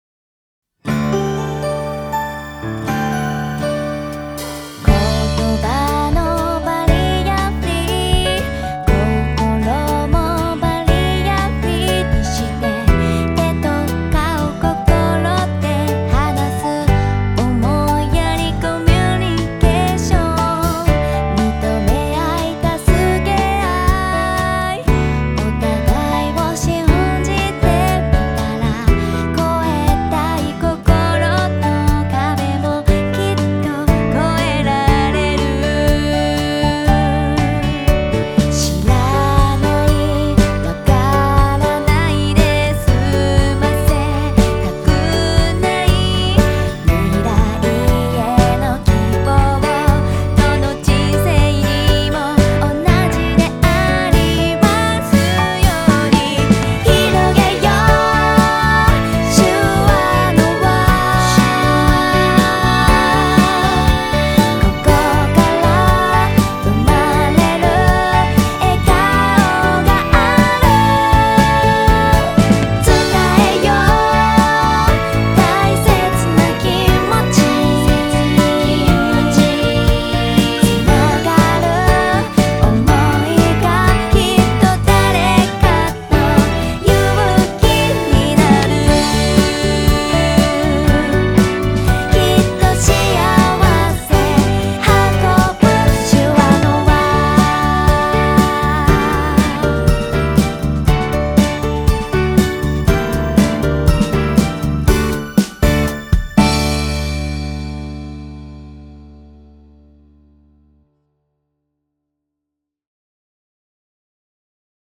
楽曲